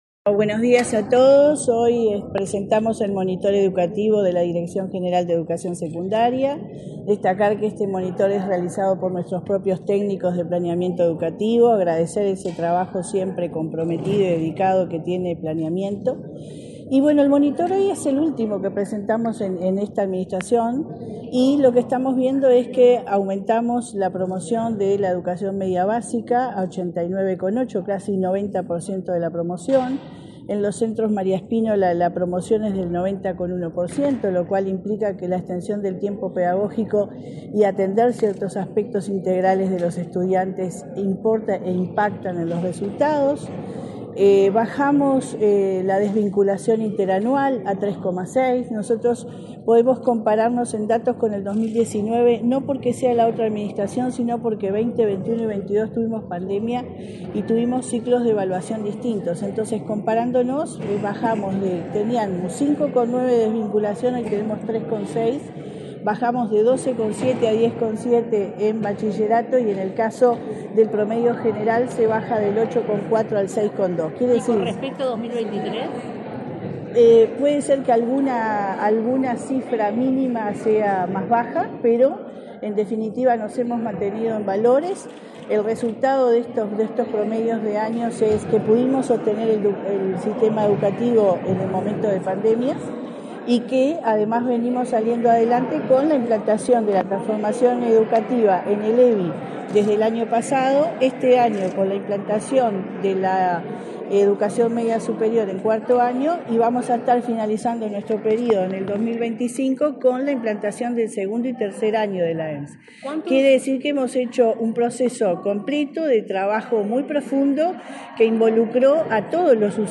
Declaraciones de la directora general de Secundaria, Jenifer Cherro
Este martes 20, la directora general de Secundaria, Jenifer Cherro, dialogó con la prensa, antes de participar en el acto de presentación de los datos